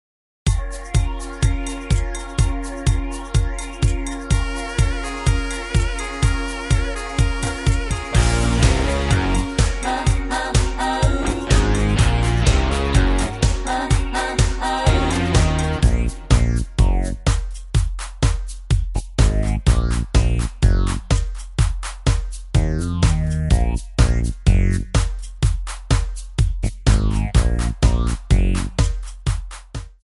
Backing track files: 1990s (2737)
Buy With Backing Vocals.